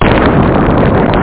boom4.mp3